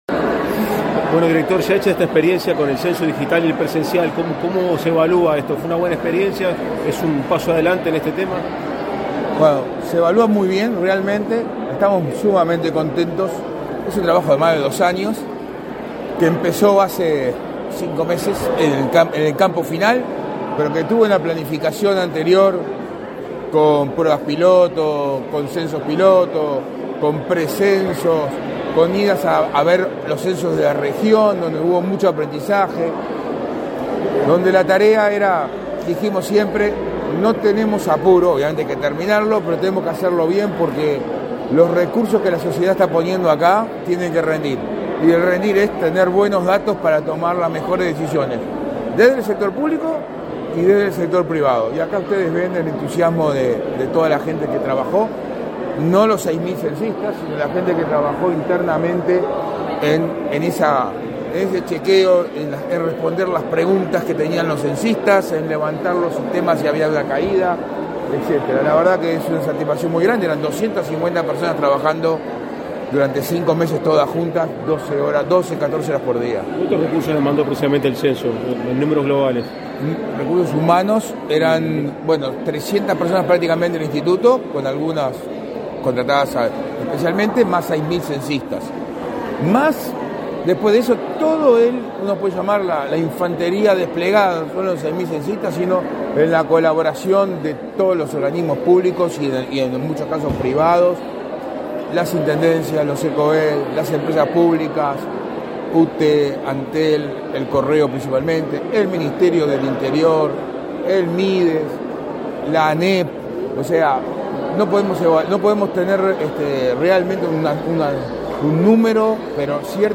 Declaraciones a la prensa del presidente de la Comisión Nacional de Censo, Isaac Alfie
Declaraciones a la prensa del presidente de la Comisión Nacional de Censo, Isaac Alfie 29/09/2023 Compartir Facebook X Copiar enlace WhatsApp LinkedIn Tras finalizar la conferencia para informar sobre el cierre del Censo 2023, este 29 de setiembre, el presidente de la Comisión Nacional de Censo, Isaac Alfie, realizó declaraciones a la prensa.